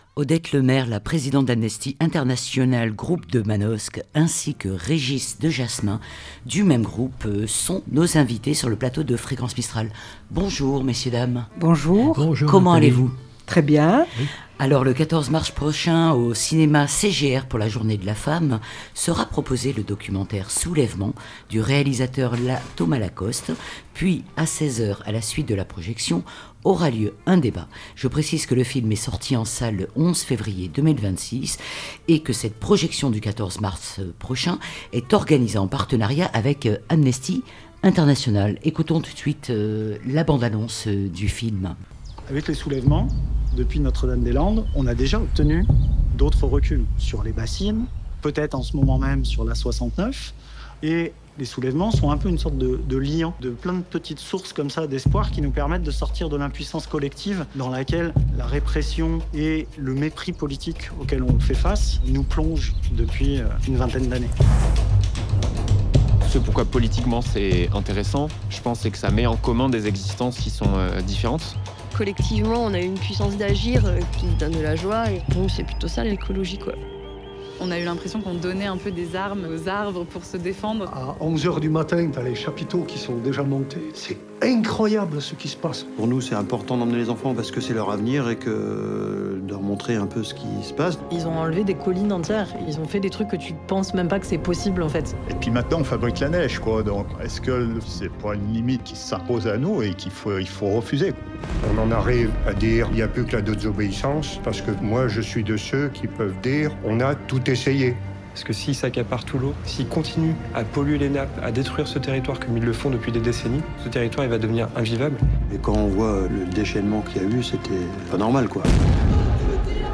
pour Amnesty International groupe de Manosque étaient les invitées du magazine " A la bonne heure " 12h10/13h00 . Le 14 mars prochain à 16h au cinéma CGR sera proposé le documentaire "Soulèvements" du réalisateur Thomas Lacoste.